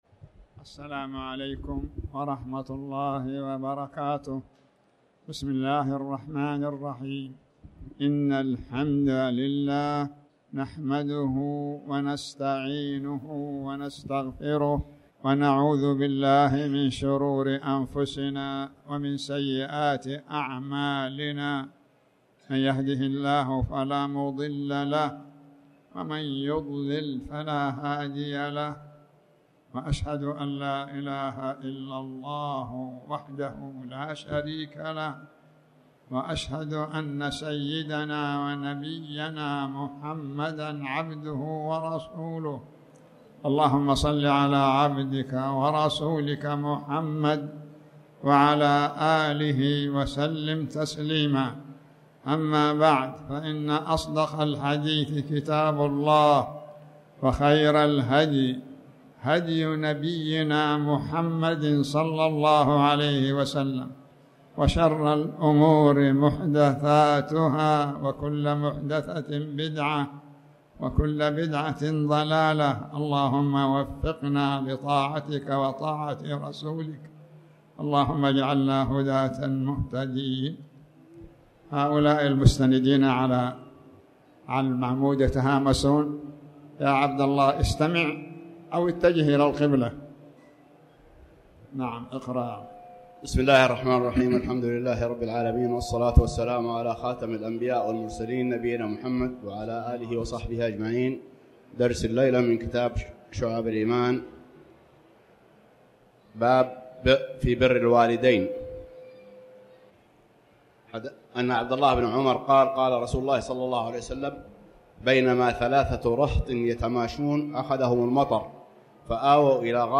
تاريخ النشر ٢٦ شوال ١٤٣٩ هـ المكان: المسجد الحرام الشيخ